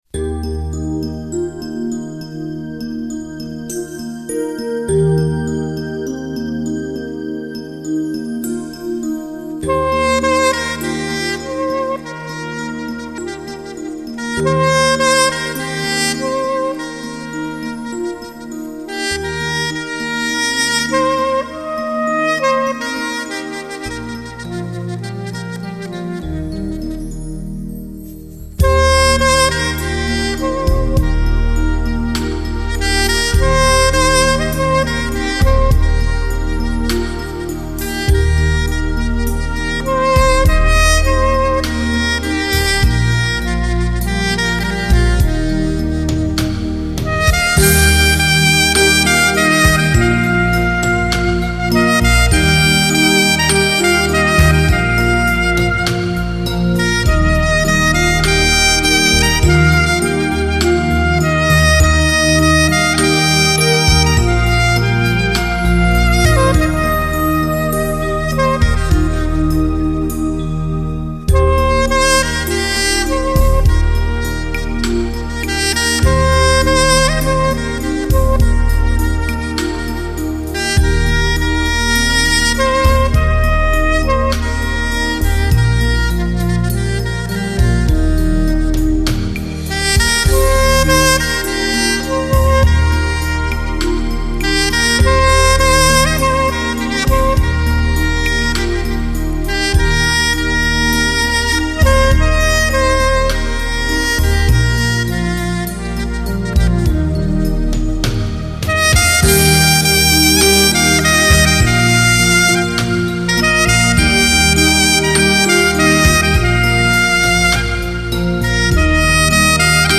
弥漫慵懒的酒 心醉情迷的音乐 如火似电般热烈  挑动人心的缠绵
萨克斯风演奏